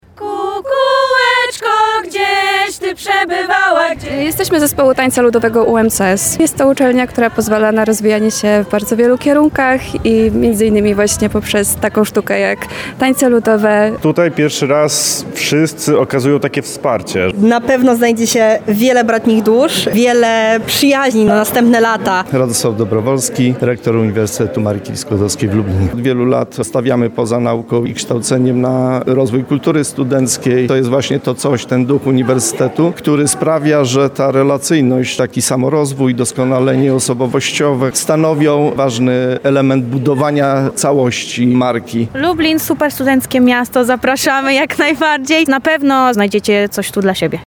Pokazy rozpraw sądowych, rekonstrukcje historyczne, a także mnóstwo warsztatów i stoisk poszczególnych kierunków czy wydziałów – na Uniwersytecie Marii Curie-Skłodowskiej w Lublinie odbył się dzień otwarty.
To największa uczelnia w regionie, w której – jak mówią studenci – oprócz nauki można rozwijać swoje pasje.